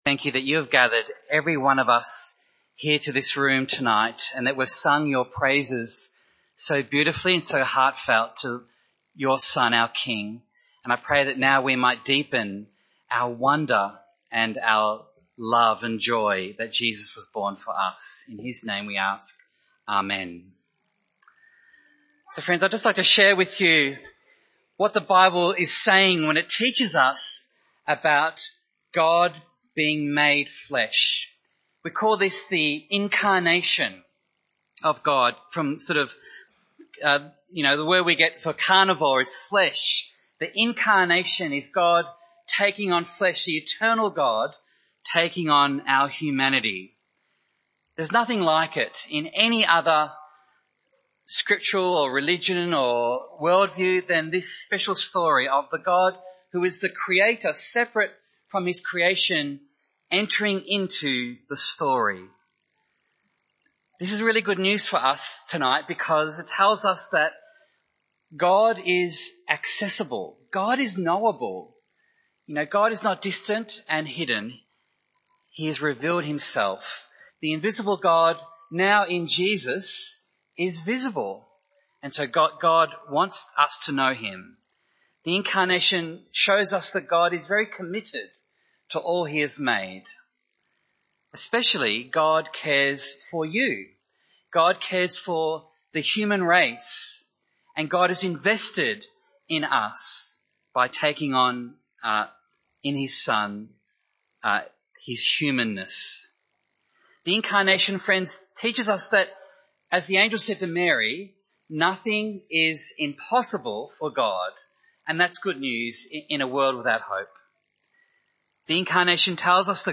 Service Type: Special Events